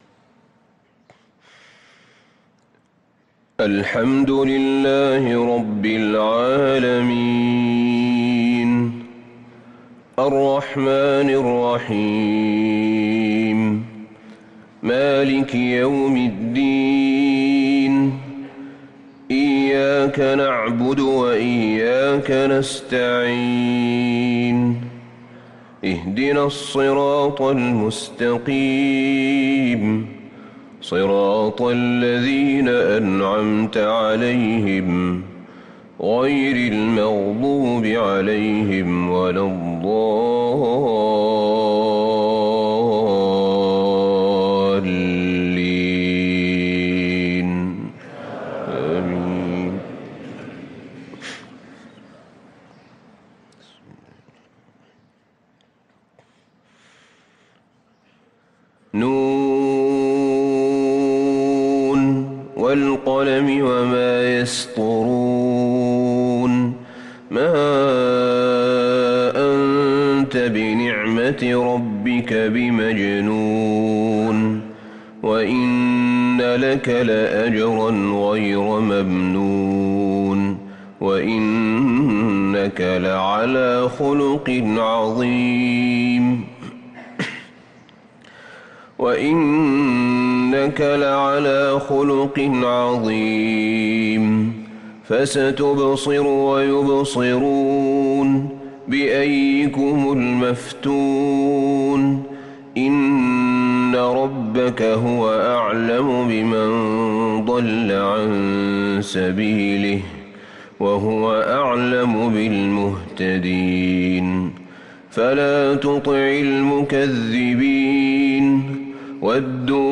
صلاة الفجر للقارئ أحمد بن طالب حميد 25 ذو الحجة 1443 هـ
تِلَاوَات الْحَرَمَيْن .